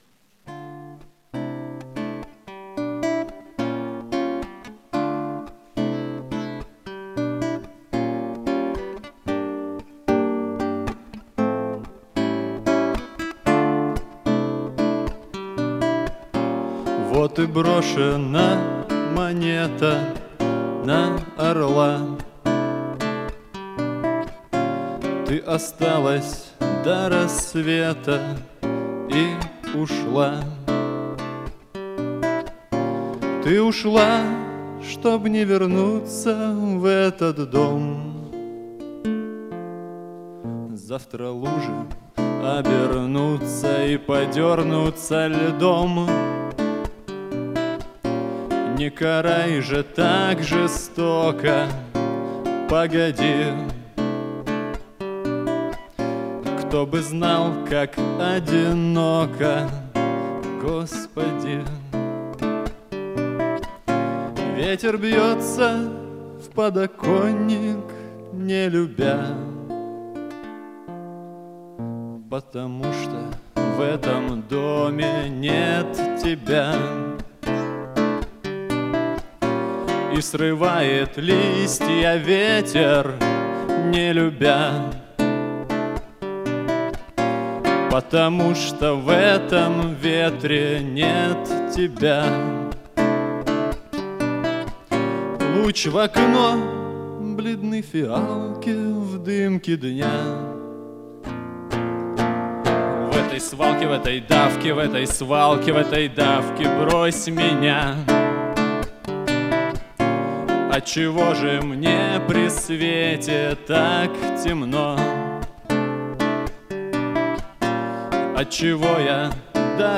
«Эхо-2013». Конкурсный концерт.